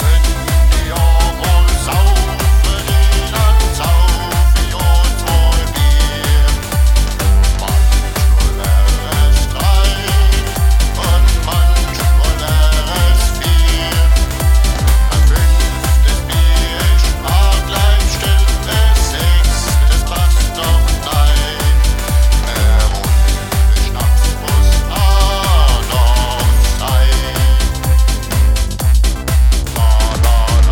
Nein, gewiss nicht Ballermann-typisch.
Bayrische traditionelle Klänge lösen Bierdurst aus :D